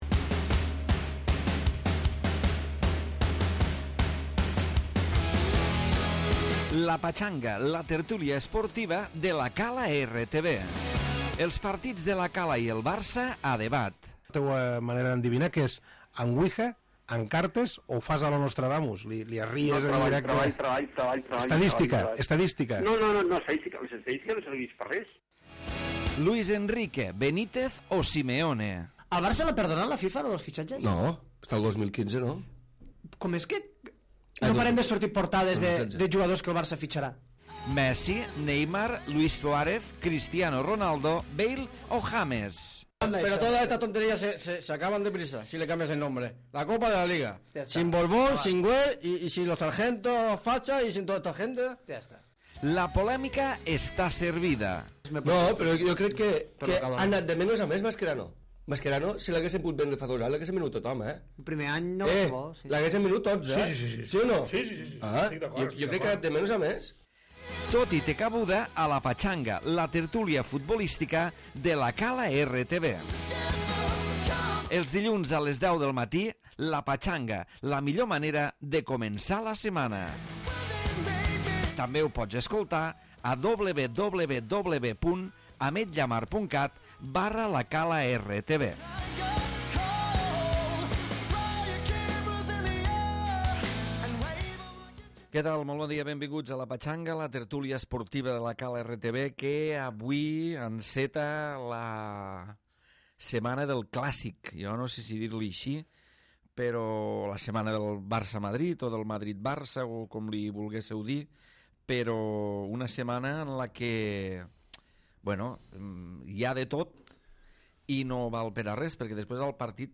tertúlia futbolística d'actualitat